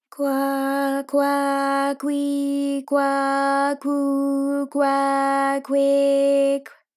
ALYS-DB-001-JPN - First Japanese UTAU vocal library of ALYS.
kwa_kwa_kwi_kwa_kwu_kwa_kwe_kw.wav